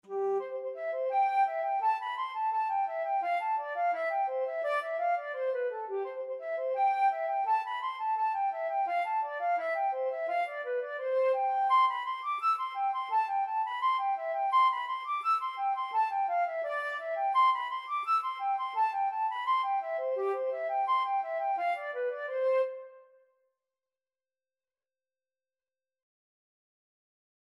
4/4 (View more 4/4 Music)
Flute  (View more Easy Flute Music)